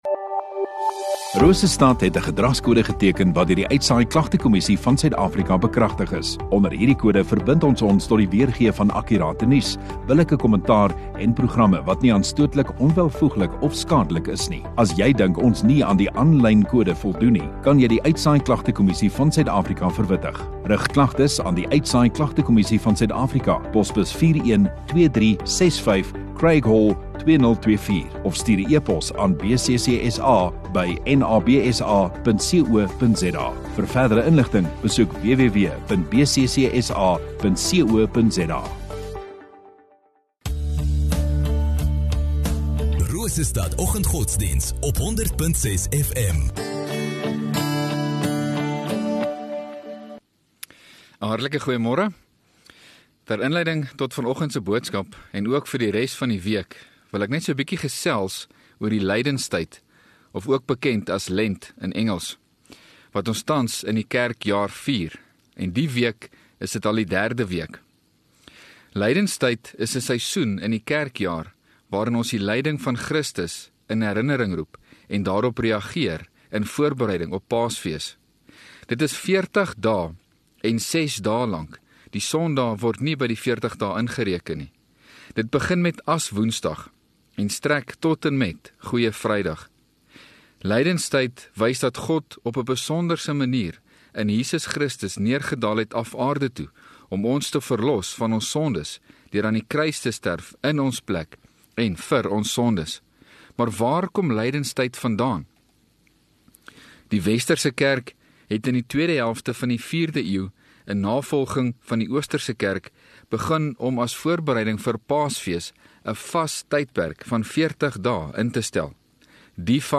2 Mar Maandag Oggenddiens